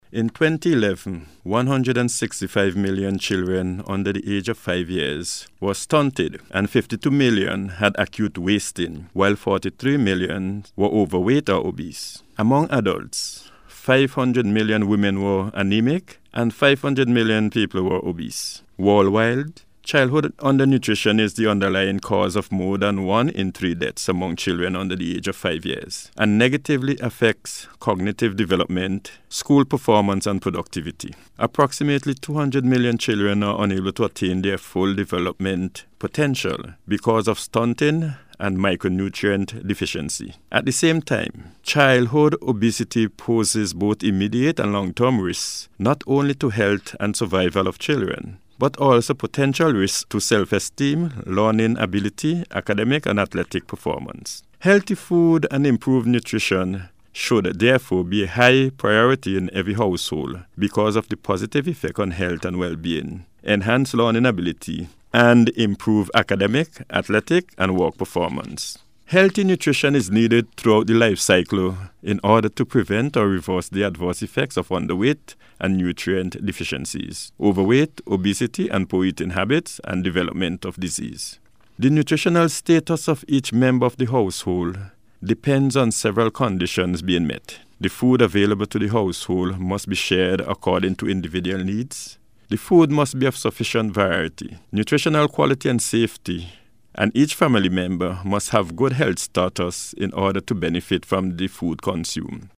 He made the point in a radio address on Sunday, to signal the start of a week of activities to observe Nutrition Awareness Week 2014.